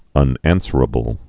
(ŭn-ănsər-ə-bəl)